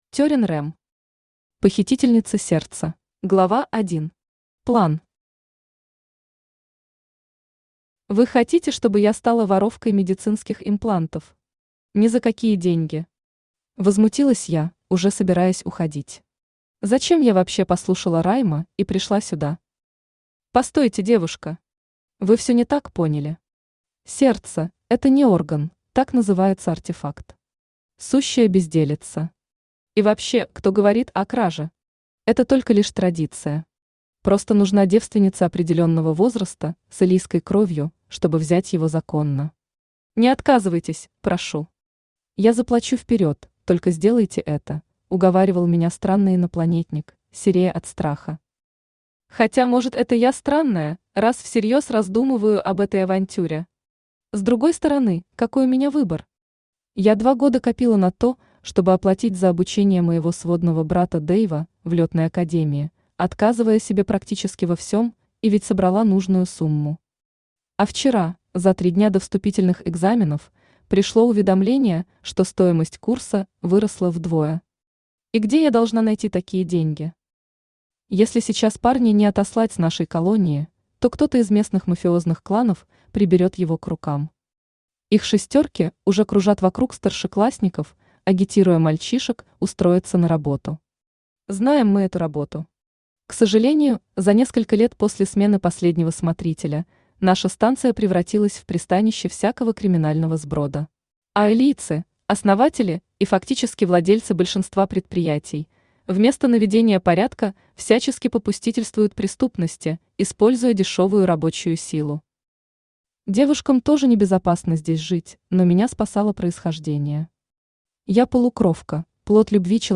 Аудиокнига Похитительница сердца | Библиотека аудиокниг
Aудиокнига Похитительница сердца Автор Терин Рем Читает аудиокнигу Авточтец ЛитРес.